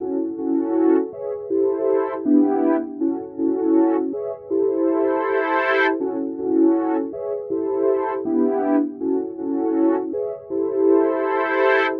Tag: 80 bpm Chill Out Loops Synth Loops 2.02 MB wav Key : A